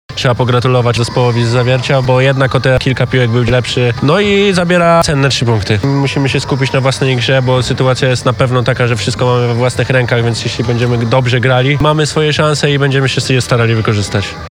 Prezentujemy pomeczowe wypowiedzi udostępnione przez Biuro Prasowe klubu MKS Ślepsk Malow Suwałki.